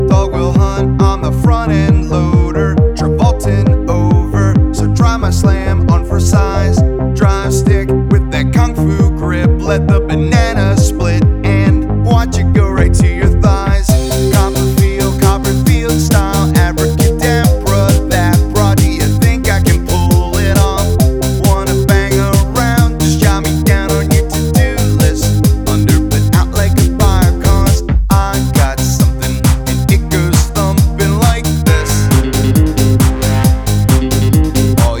Жанр: Рок / Альтернатива / Метал